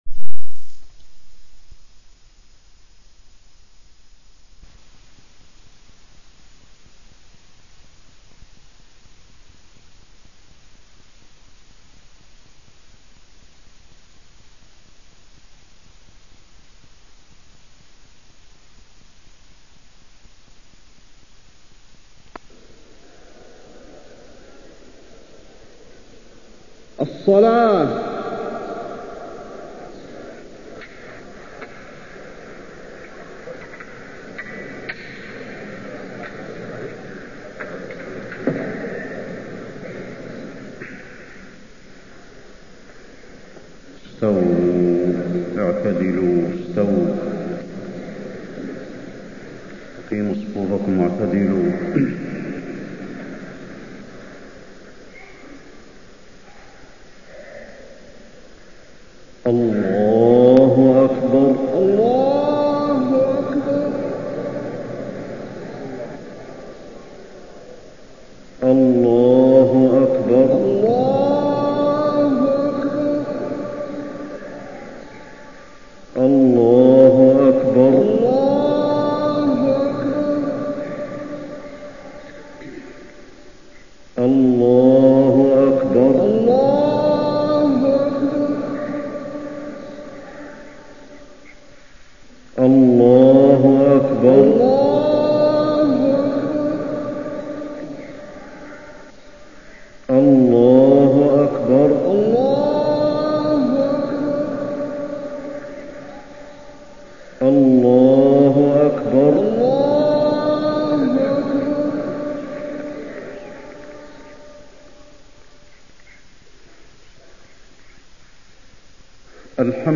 خطبة عيد الأضحى - المدينة - الشيخ علي الحذيفي - الموقع الرسمي لرئاسة الشؤون الدينية بالمسجد النبوي والمسجد الحرام
المكان: المسجد النبوي